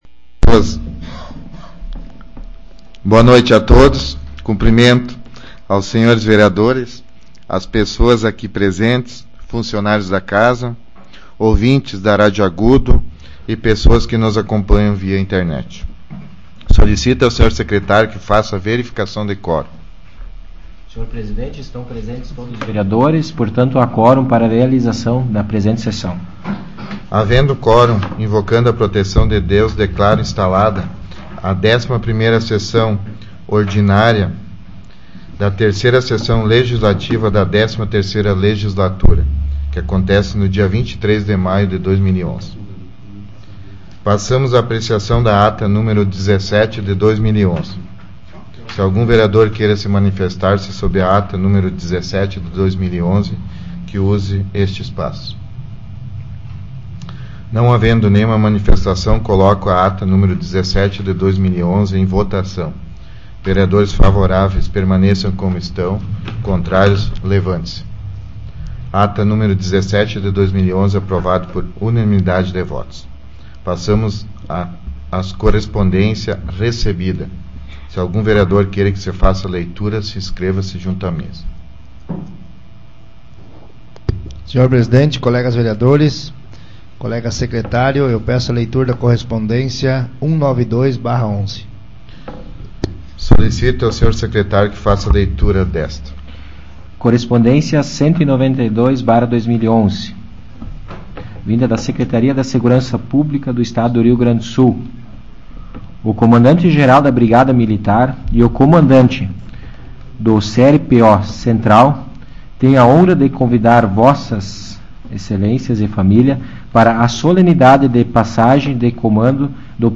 Tipo de Sessão: Ordinária